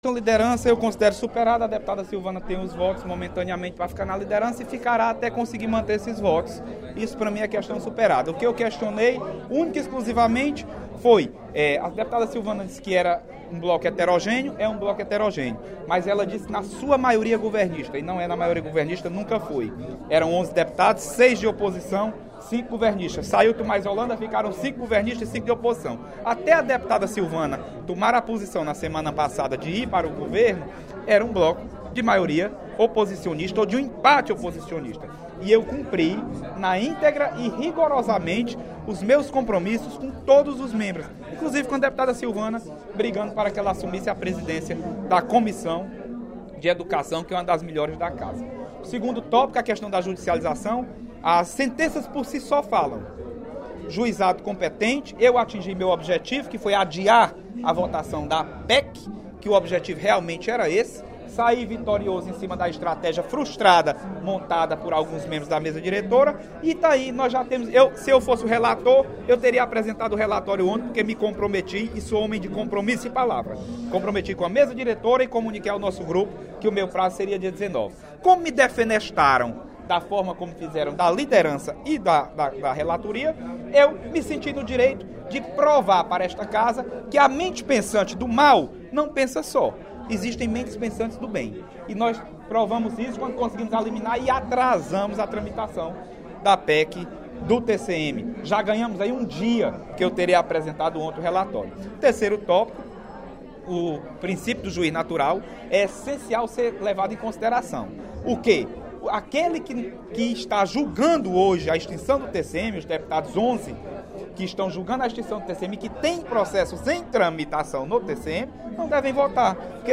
O deputado Leonardo Araújo (PMDB) esclareceu, nesta terça-feira (20/06), durante o primeiro expediente da sessão plenária, pontos envolvendo a troca da liderança do bloco PMDB-PMB-PSD e a judicialização da PEC que põe fim no Tribunal de Contas dos Municípios.